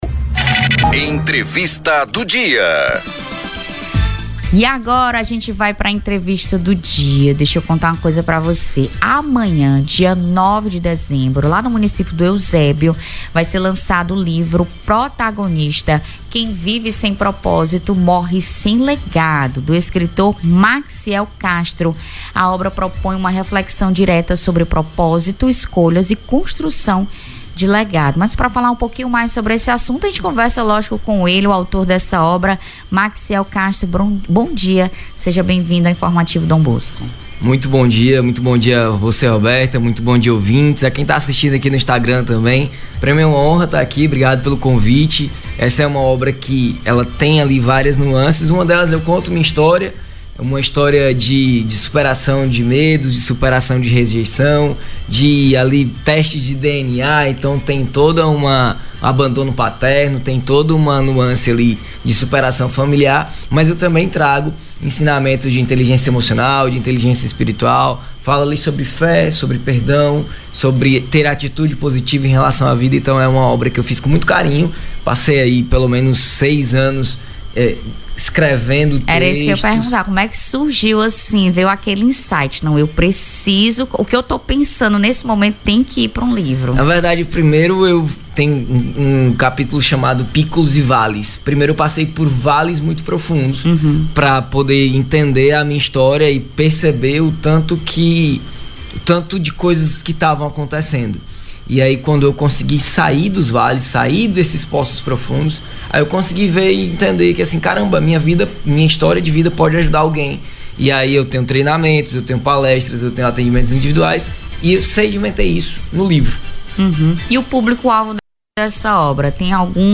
ENTREVISTA-0812.mp3